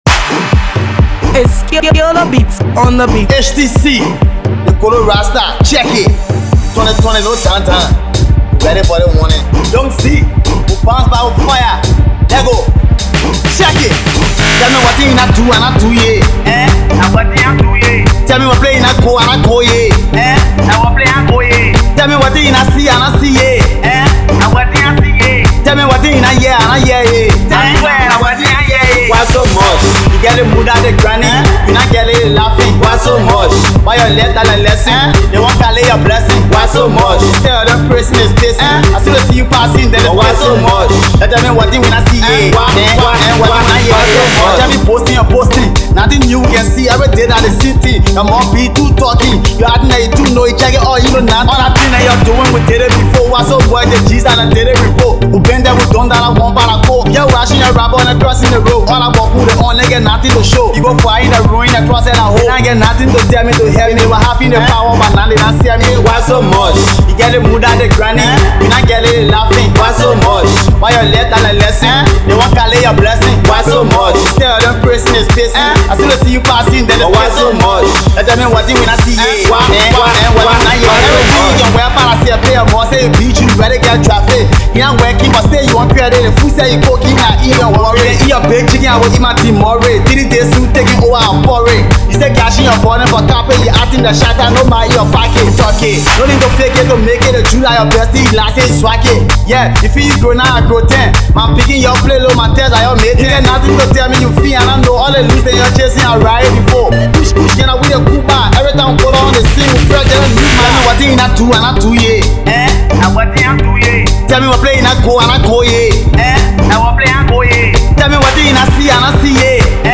/ Hip-Co, Colloquial / By